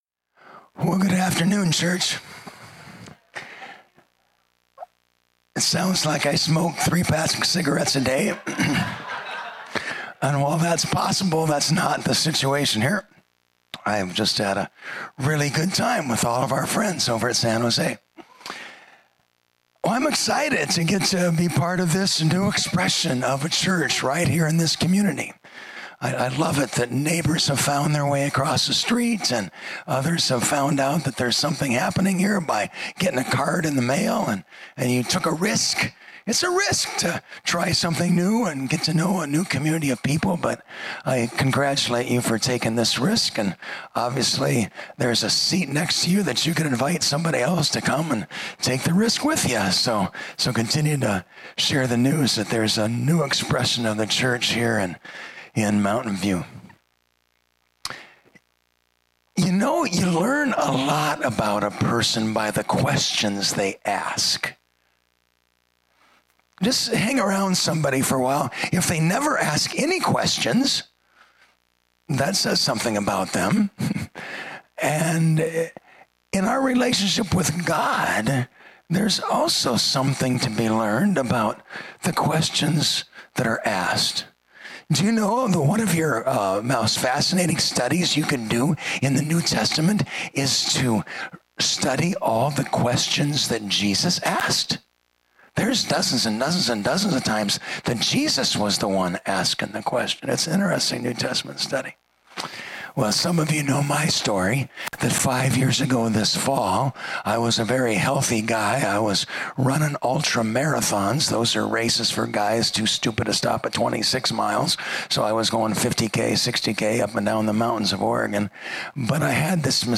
Recent Sermons
Guest Speaker